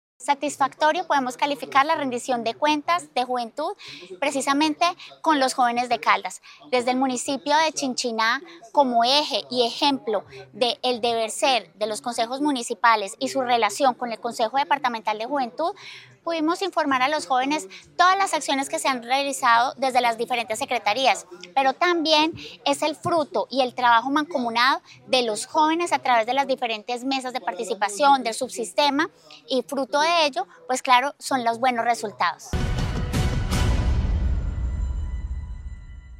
Cerca de 100 jóvenes de todo Caldas llegaron hasta Cameguadua, en Chinchiná, para asistir a la Rendición de Cuentas de Juventud 2025, liderada por la Secretaría de Integración y Desarrollo Social del departamento.
Sandra Patricia Álvarez Castro, secretaria de Integración y Desarrollo Social.